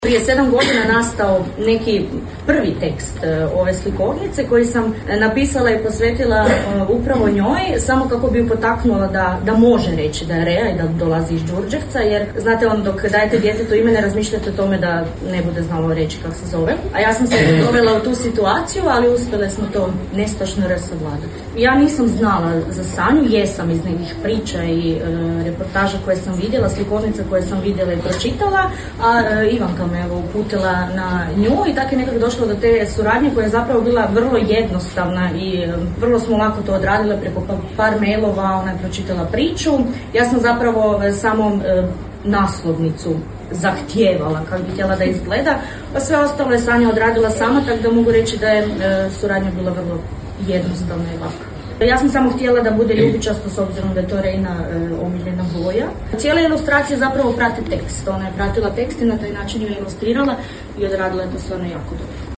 U Gradskoj knjižnici Đurđevac održano je predstavljanje slikovnice „Nestašno R“